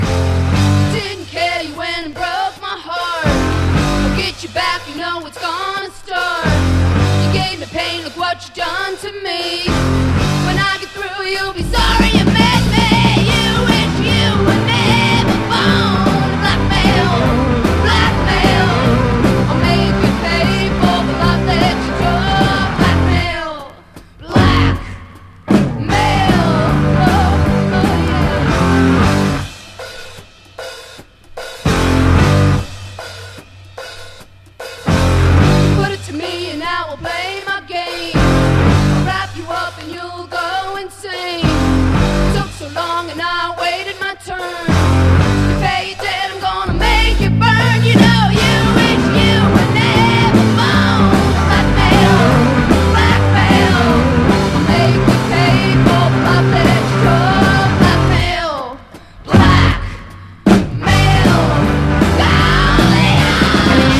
INDIE POP / SURF / GARAGE / HOT ROD / GARAGE PUNK / INSTRO